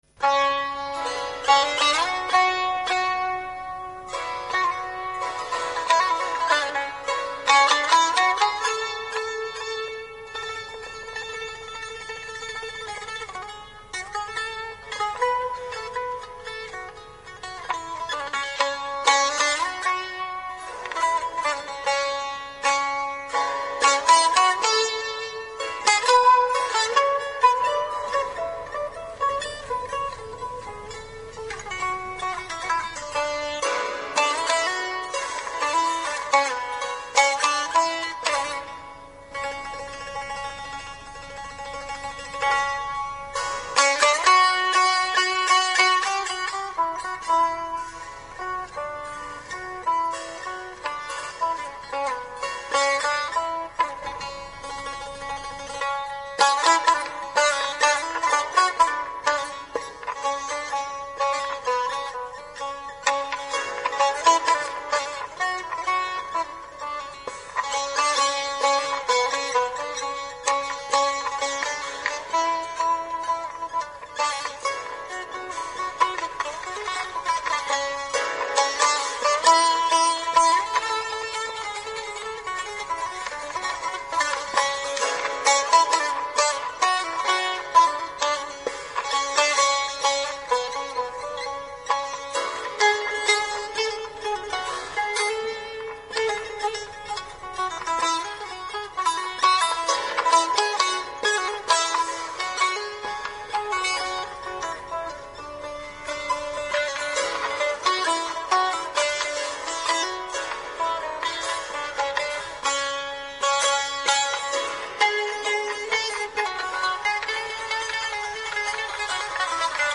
The instrument is similar in size and shape to a sitar.
They are tuned to the traditional Afghan melody and vibrate without being touched, ringing from the harmonic overtones within the resonating chamber.
Mullah Momad Jan" on tambur